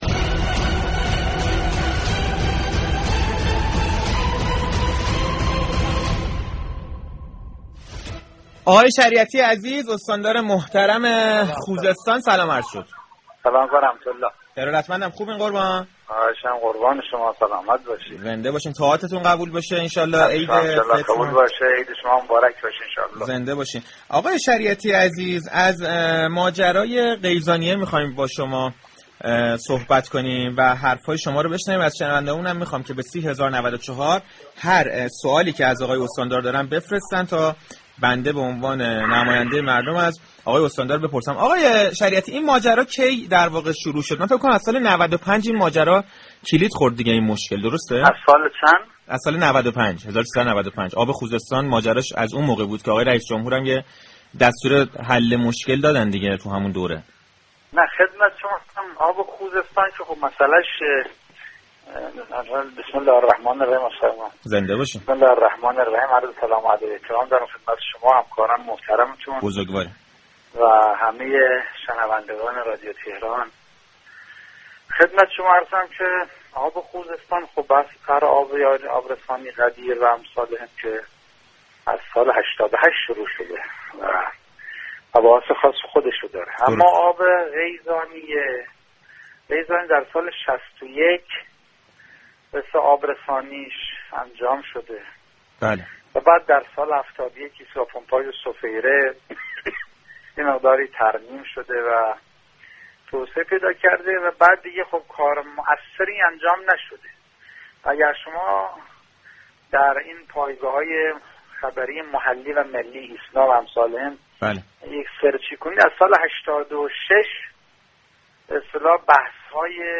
استاندار خوزستان در گفتگوی تلفنی با برنامه پشت صحنه 6 خردادماه توضیحاتی را درخصوص آبرسانی به منطقه غیزانیه ارائه داد.